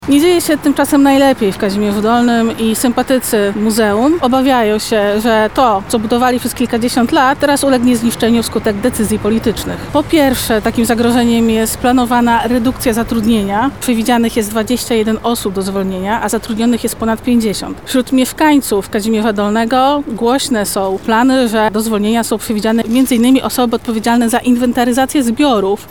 Dzisiaj (29.01) na placu Teatralnym pod Centrum Spotkania Kultur w Lublinie, odbyła się konferencja z udziałem aktywistów ugrupowania. Rozmowa dotyczyła problemów związanych z członkami Muzeum Nadwiślańskiego w Kazimierzu Dolnym, spowodowanych działaniami Marszałka Jarosława Stawiarskiego.